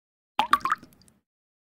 20. drip